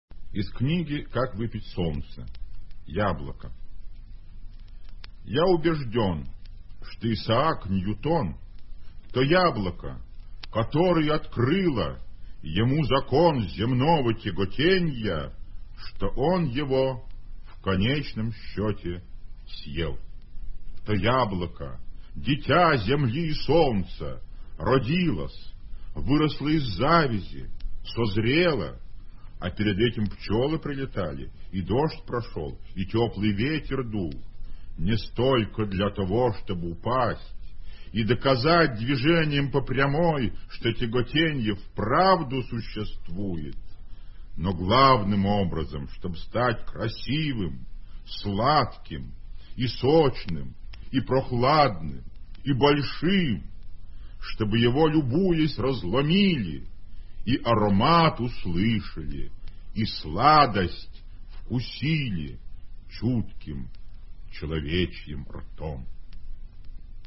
1. «Владимир Солоухин – Яблоко (читает автор)» /
Solouhin-Yabloko-chitaet-avtor-stih-club-ru.mp3